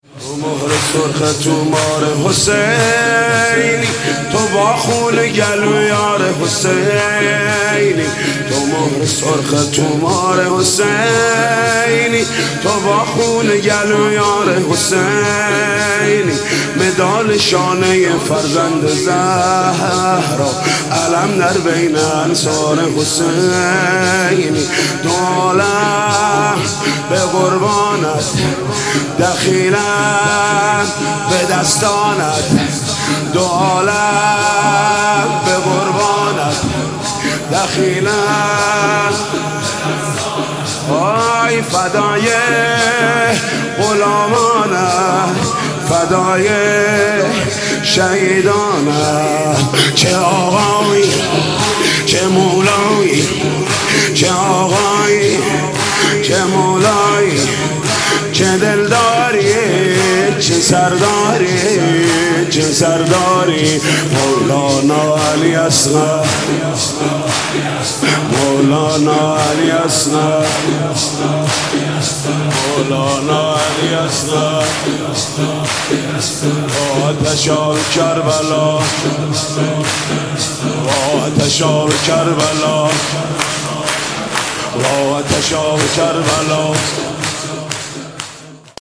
تک جدید